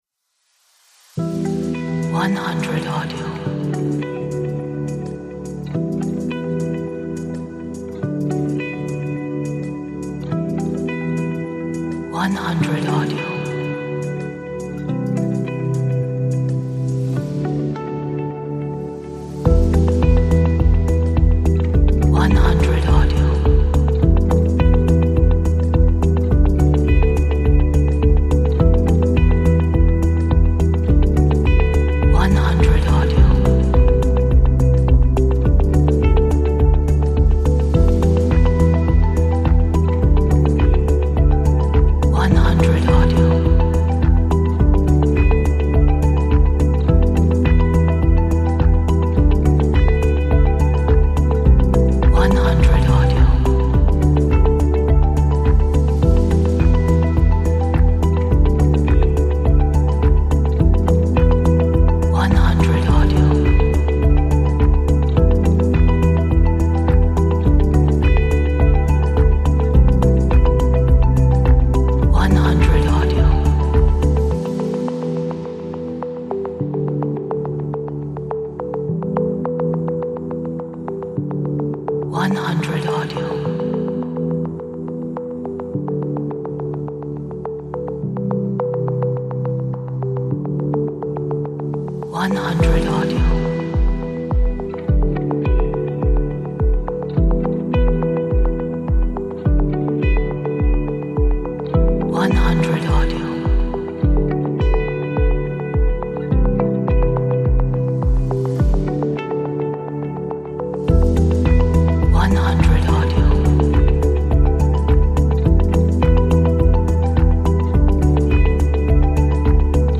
Relaxing ambient track.